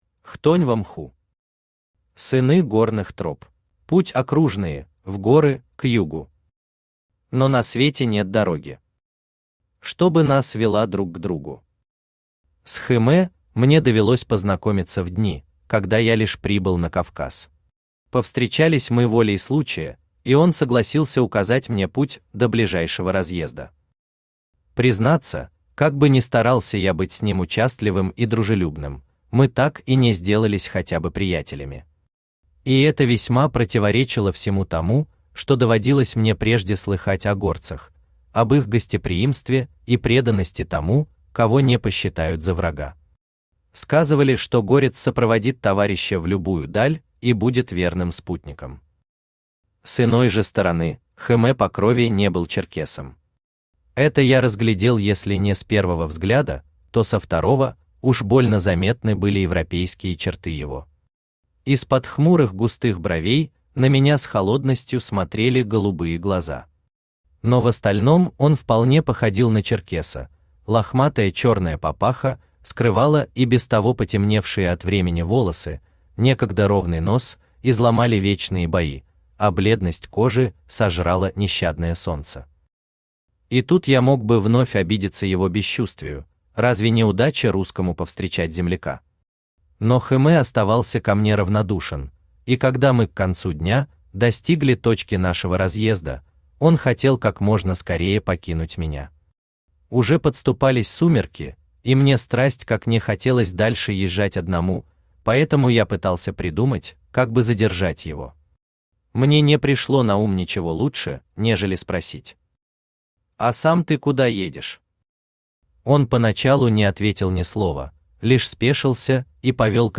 Aудиокнига Сыны горных троп Автор Хтонь во мху Читает аудиокнигу Авточтец ЛитРес.